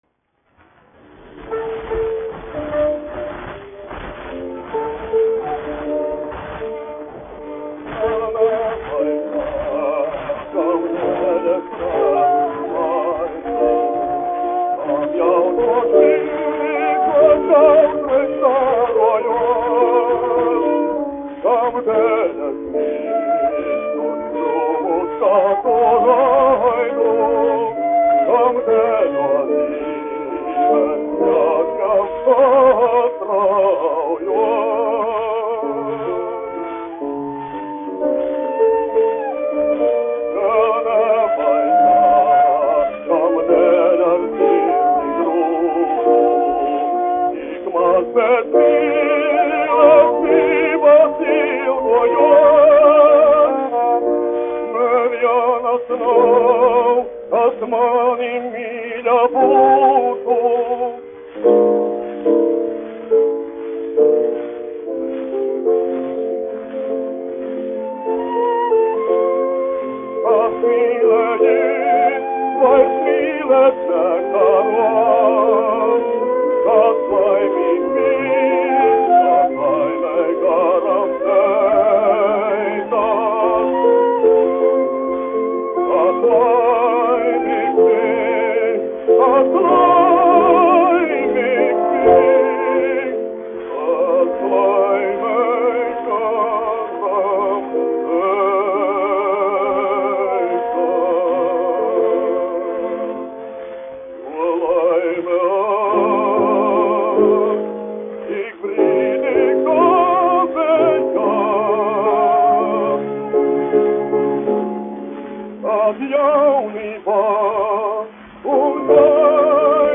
Kaktiņš, Ādolfs, 1885-1965, dziedātājs
Jāzeps Mediņš, 1877-1947, instrumentālists
1 skpl. : analogs, 78 apgr/min, mono ; 25 cm
Dziesmas (zema balss) ar instrumentālu ansambli
Latvijas vēsturiskie šellaka skaņuplašu ieraksti (Kolekcija)